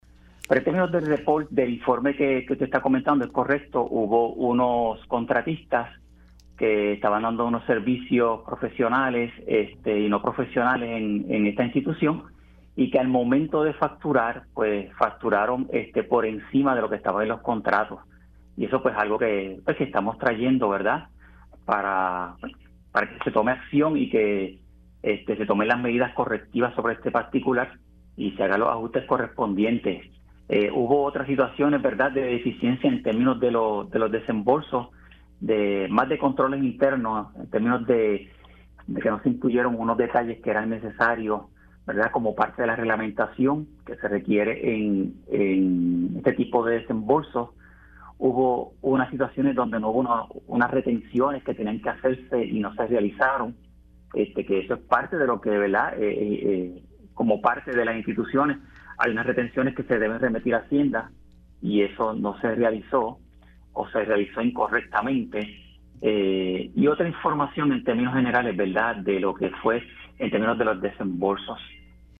El subcontralor, David de Jesús, explicó en Pega’os en la Mañana que los contratistas facturaron por una cantidad mayor a la que se indicaba en su contrato, y que se emitió información incompleta a la hora de manejar los desembolsos.